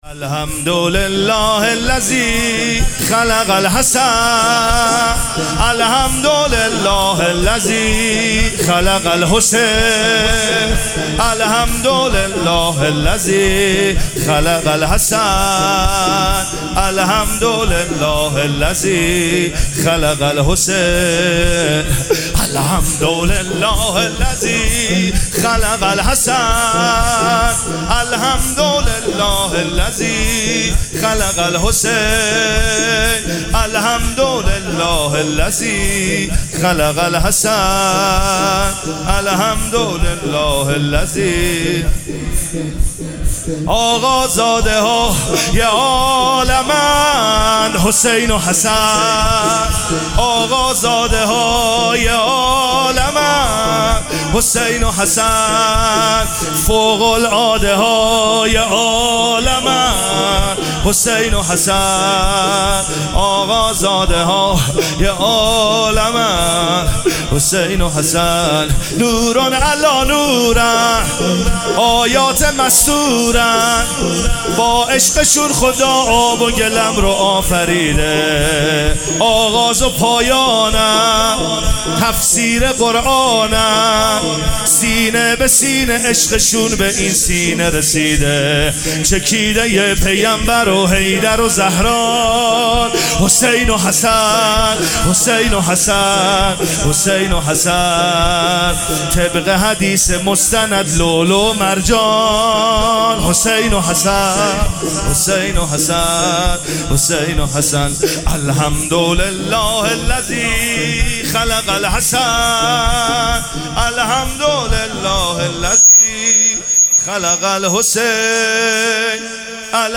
شب پنجم ماه صفر 1399